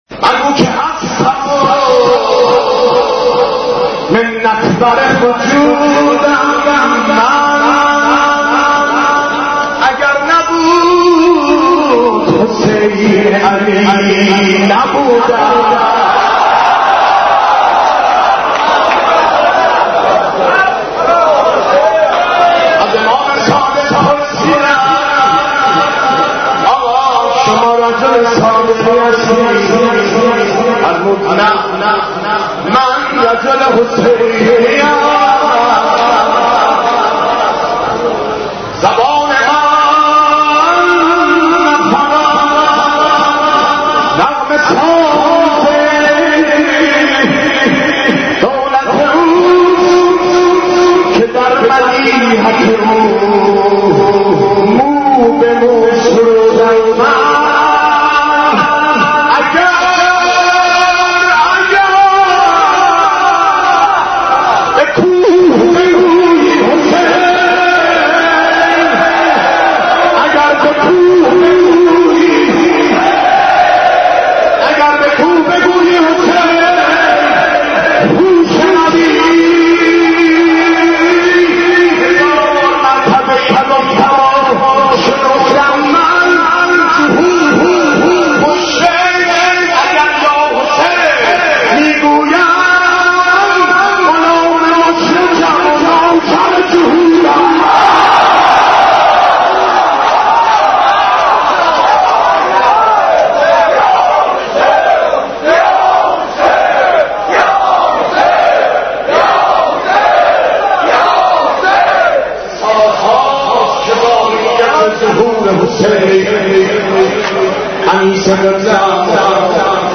مداحی امام حسین ع 13